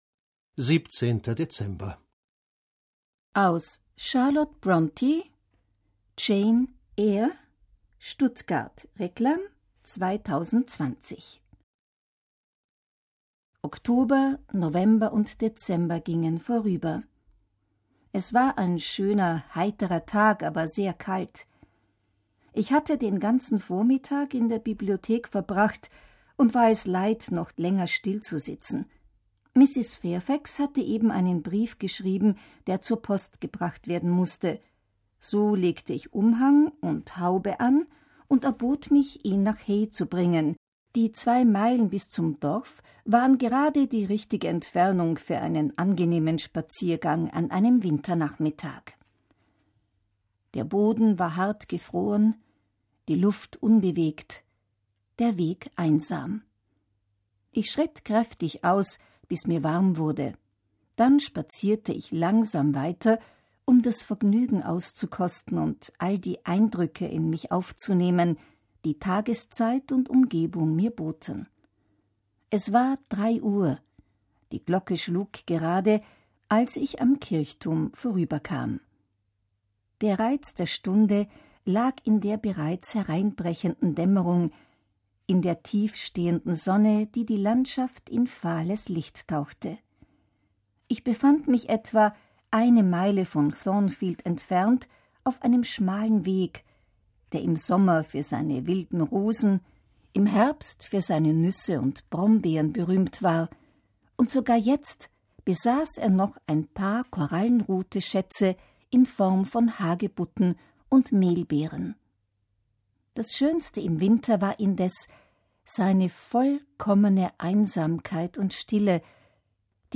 Freuen Sie sich heute auf die Begegnung mit der Heldin aus einem echten Klassiker der Weltliteratur: Charlotte Brontës "Jane Eyre". Hier in Ihrem Adventskalender zum Hören!